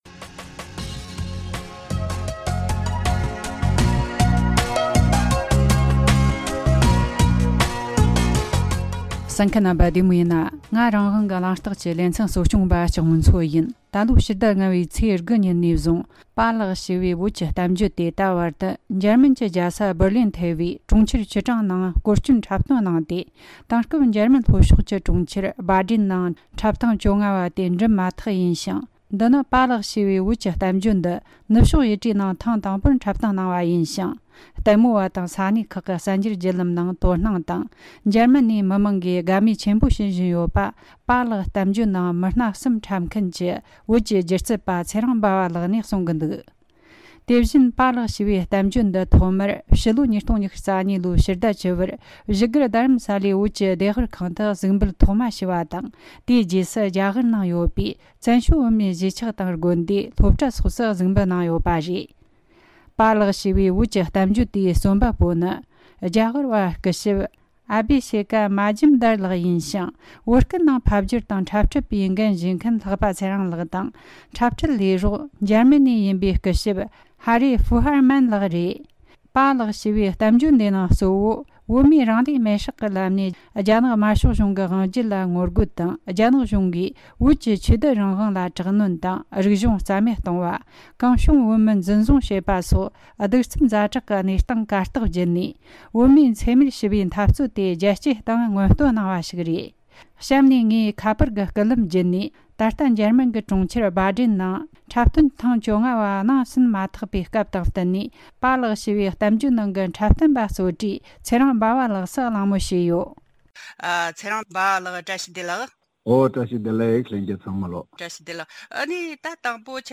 གླེང་མོལ་བྱས་བར་གསན་རོགས་གནོངས།